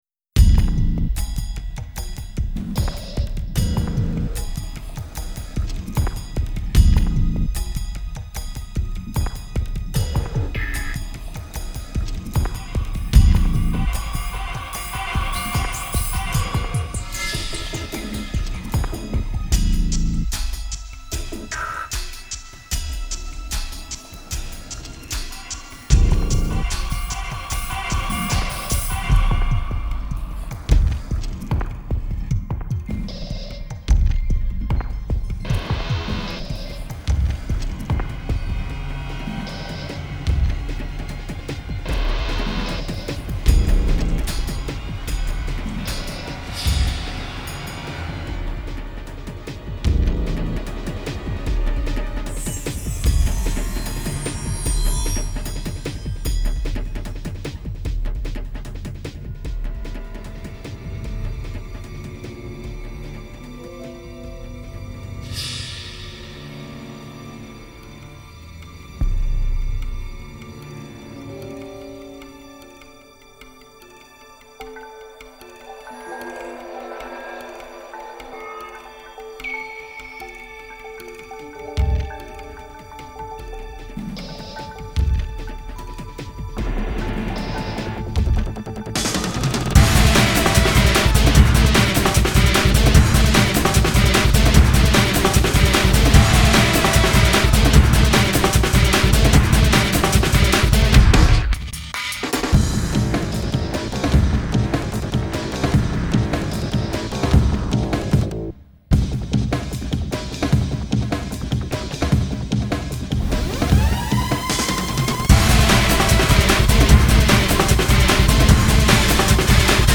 专辑类型：OST